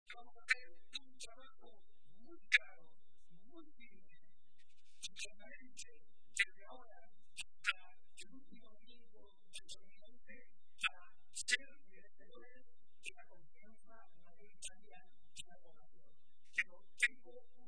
Barreda realizó estas manifestaciones durante la celebración de la tradicional comida navideña del PSOE de Toledo, que ha tenido lugar hoy en la capital regional, y a la que también asistió la ministra de Sanidad y Política Social, Trinidad Jiménez.